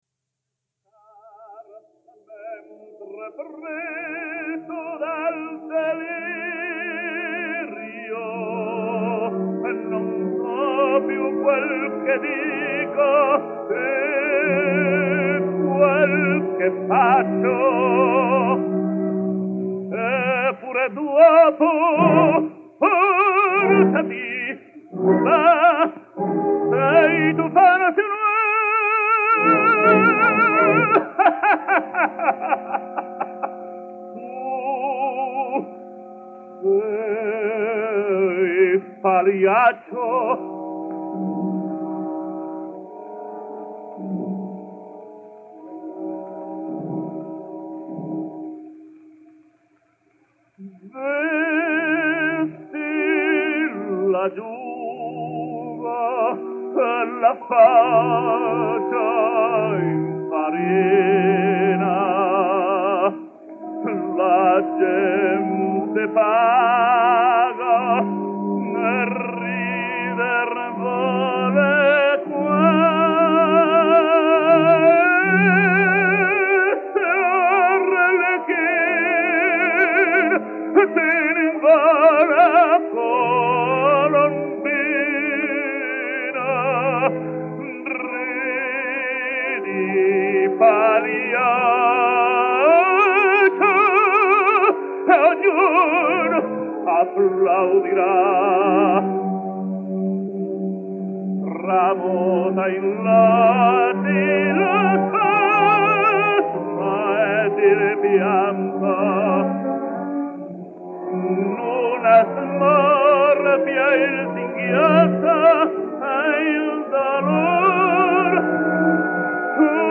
Puerto Rican Tenor.